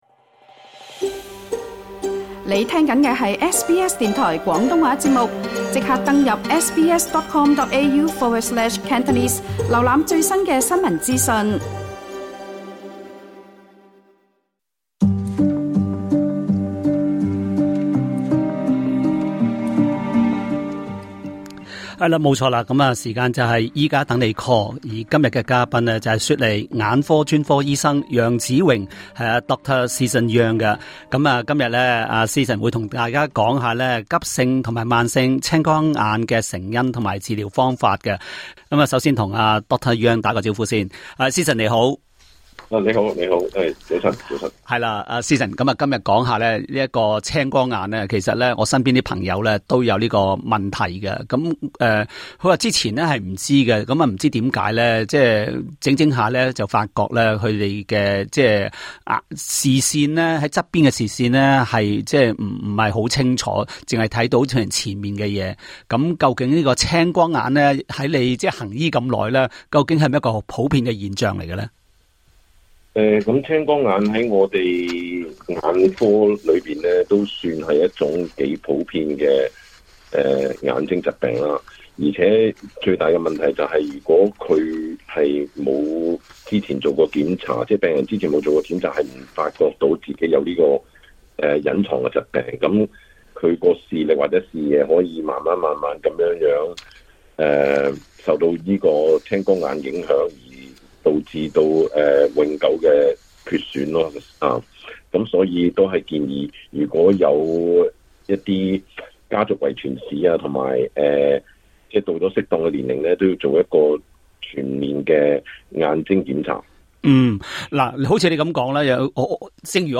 他還接聽聽眾來電，就他們的眼疾逐一講解。